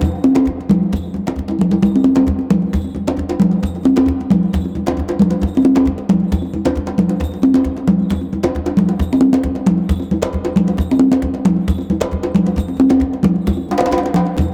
CONGABEAT5-L.wav